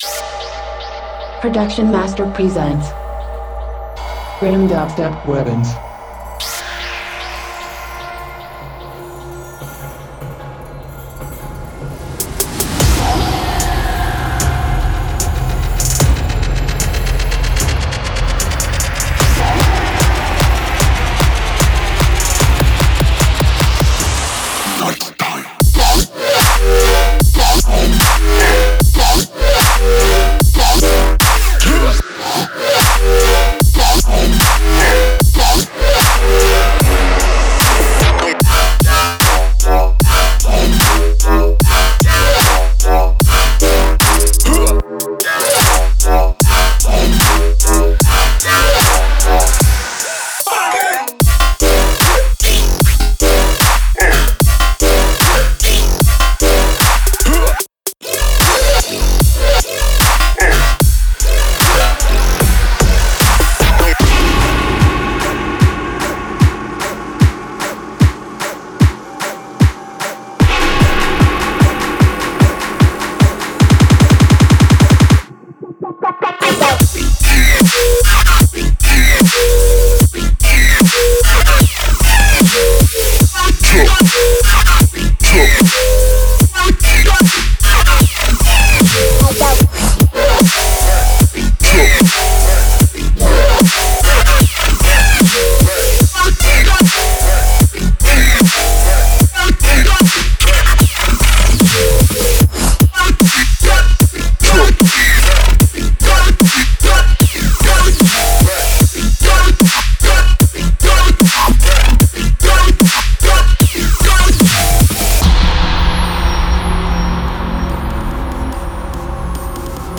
想象一下一个不可阻挡的穿甲步枪样本和预设的武器库。